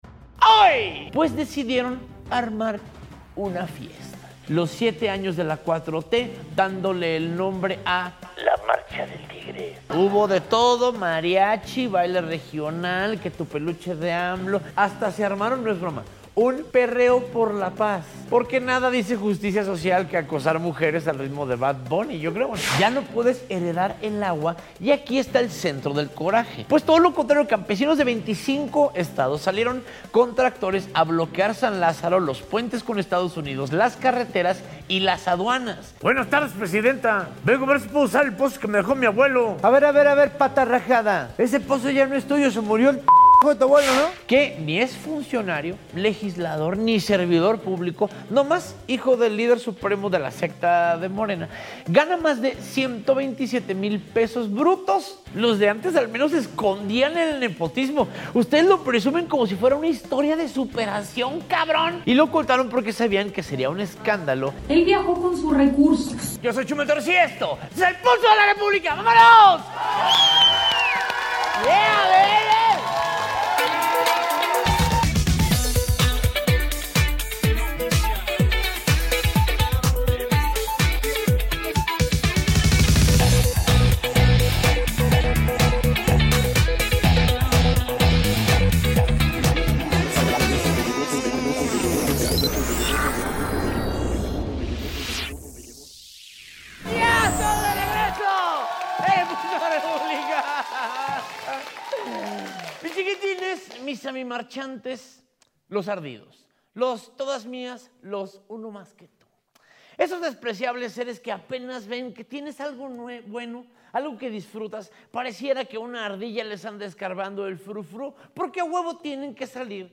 El InIn (Informativo Informal) que sale todos los LUNES y JUEVES a las 8 PM EN VIVO.